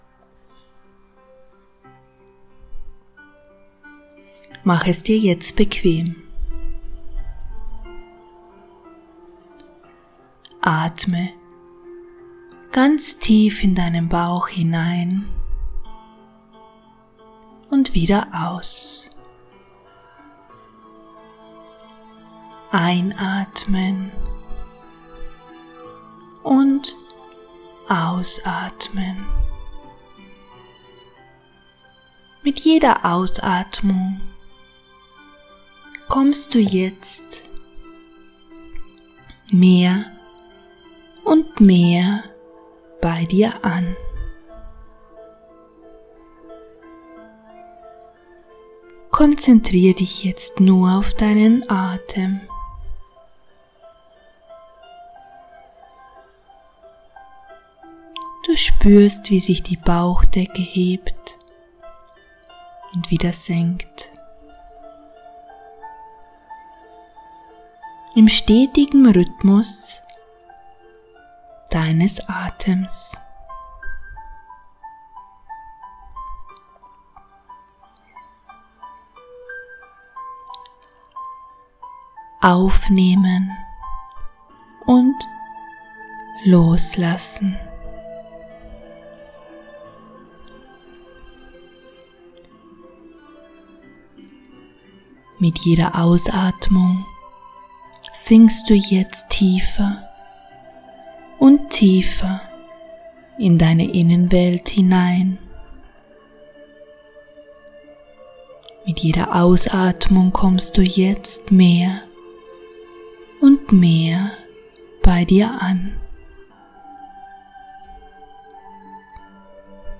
TierTalk-Meditation-Aura-Essenz-Baer.mp3